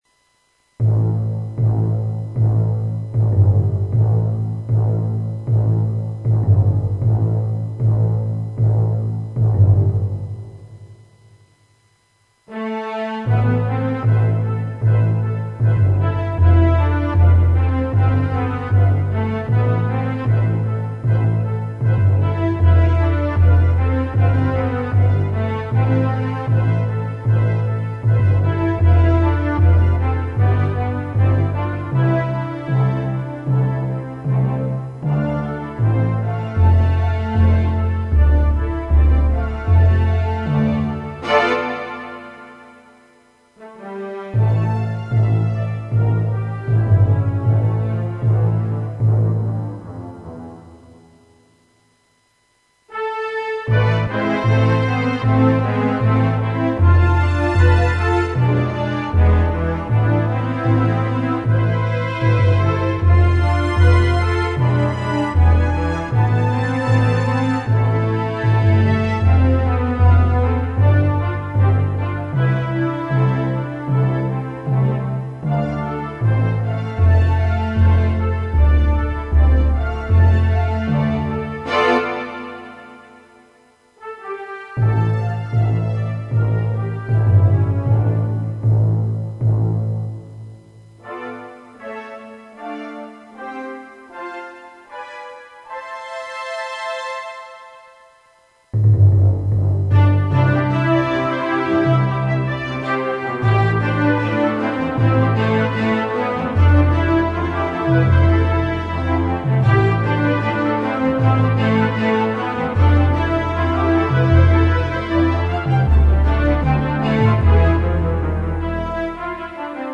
REC STUDIO FAIRY DANCE